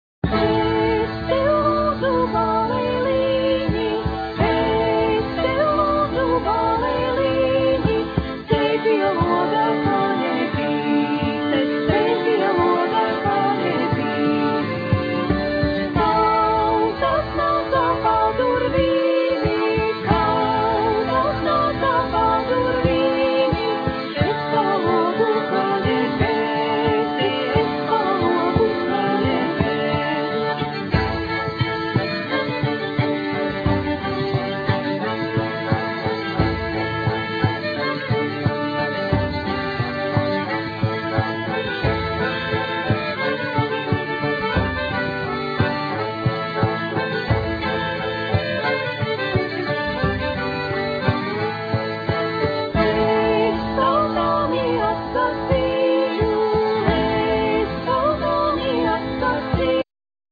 Vocal,Violin,Fiddle,Kokle
Vocal,Giga
Vocal,Kokle,Bagpipe,Acordeon
Guitar
Bungas,Sietins,Bodrans
Programming,Bass,Giga
Mazas bungas